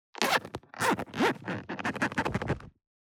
433,ジッパー,チャックの音,洋服関係音,
ジッパー